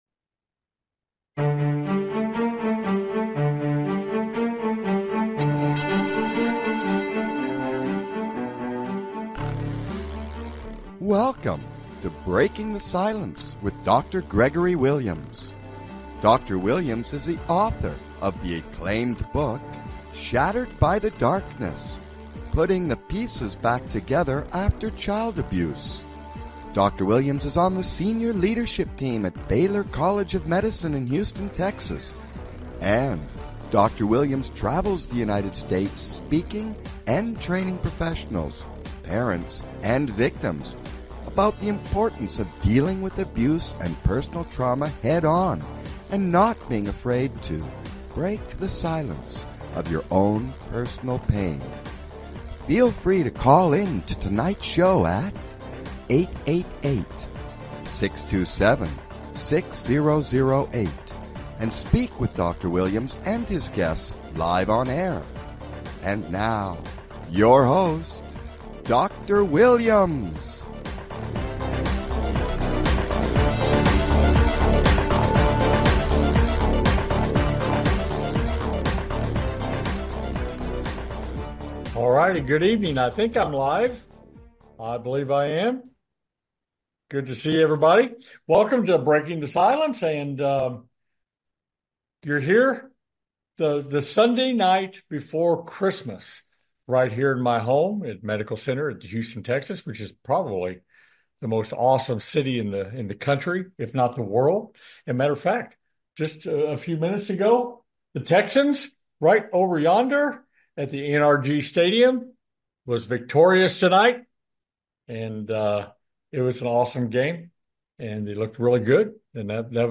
Talk Show Episode, Audio Podcast, Breaking the Silence and A Merry Christmas Special, The Sunday Night Before Christmas on , show guests , about A Merry Christmas Special, categorized as Health & Lifestyle,Kids & Family,Psychology,Emotional Health and Freedom,Mental Health,Self Help,Inspirational,Motivational,Society and Culture